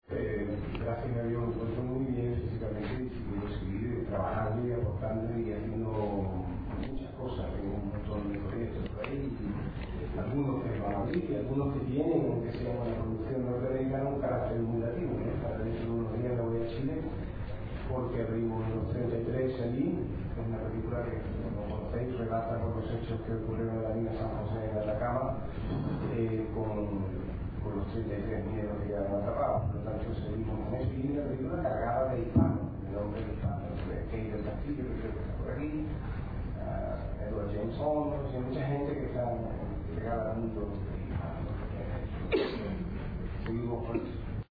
Así de rotundo se mostró hoy el actor, productor y director Antonio Banderas en una rueda de prensa en Marbella (sur de España).
Banderas hable de su nueva película